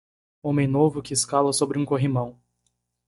Read more Noun Verb escalar Adj Verb Verb Read more Frequency B2 Pronounced as (IPA) /isˈka.lɐ/ Etymology Borrowed from Latin scāla Borrowed from Italian scala In summary Learned borrowing from Latin scāla.